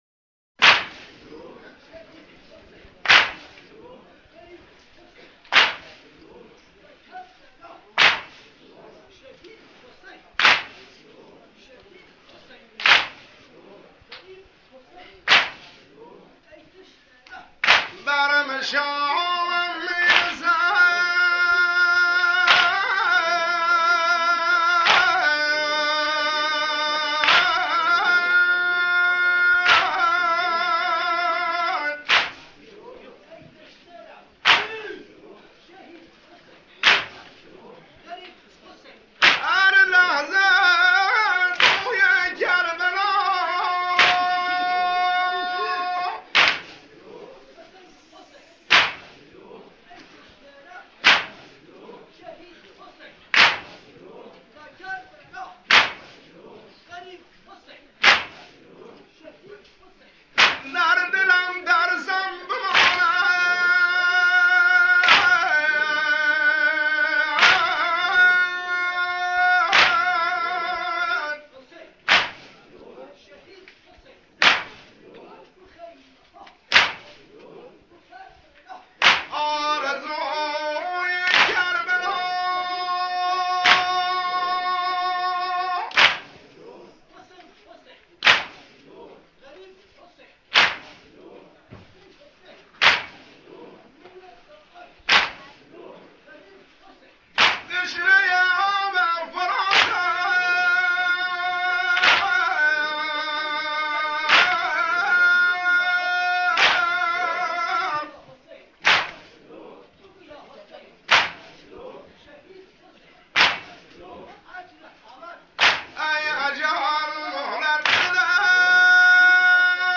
وبلاگ تخصصی نوحه های سنتی بوشهر
چاوشی اربعین